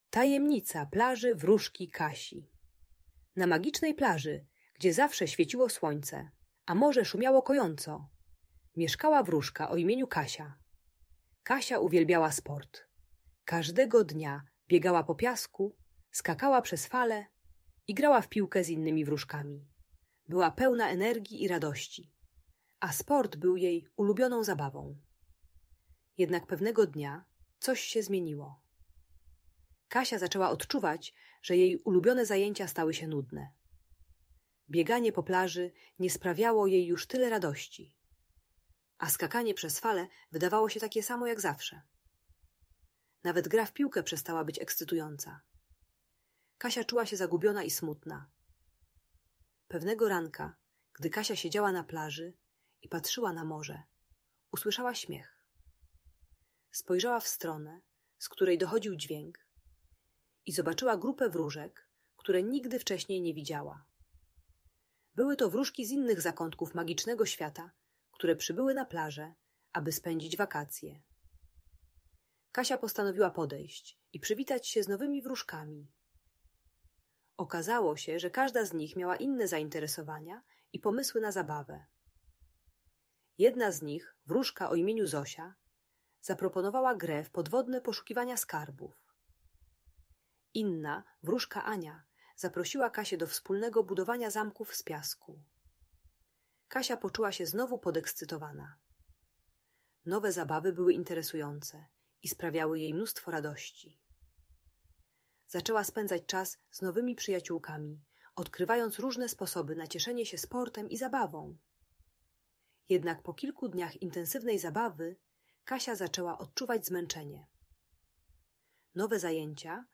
Tajemnicza Plaża Wróżki Kasi - Audiobajka dla dzieci